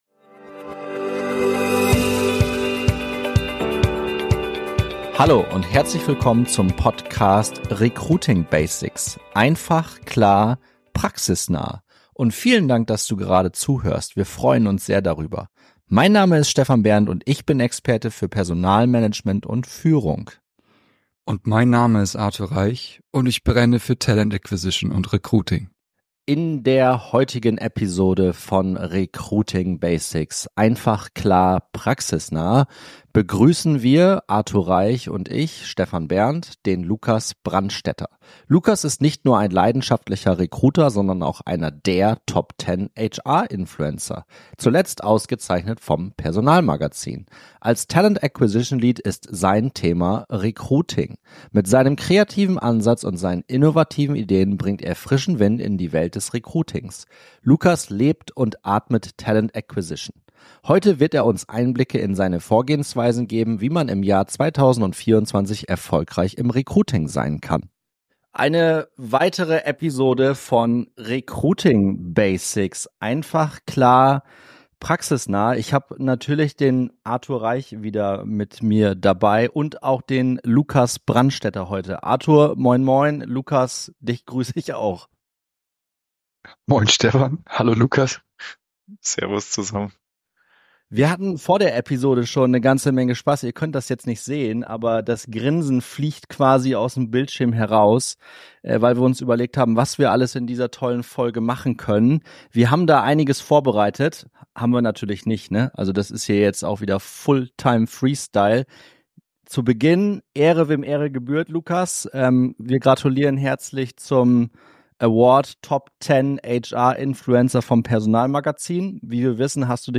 Die drei diskutieren lebhaft über die Faszination und Herausforderungen im Recruiting-Jahr 2024.